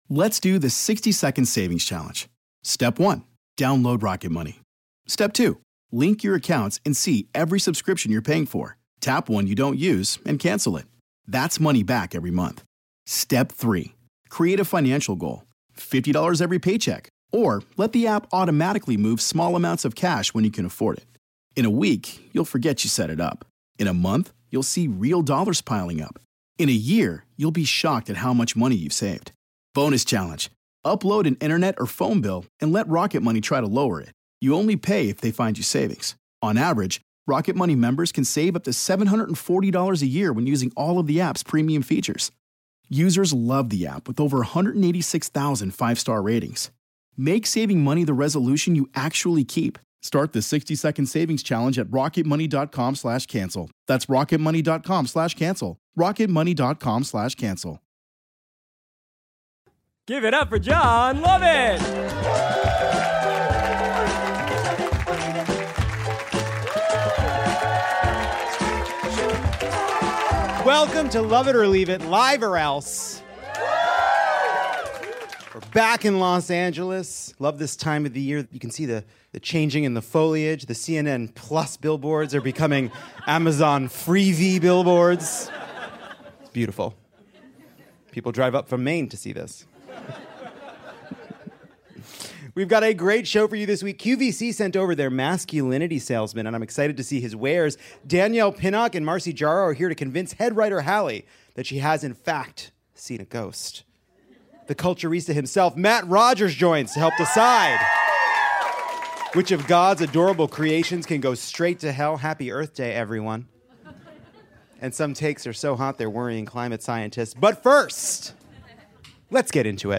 Spring has sprung and Lovett or Leave It is blooming all over Los Angeles’ Dynasty Typewriter once again.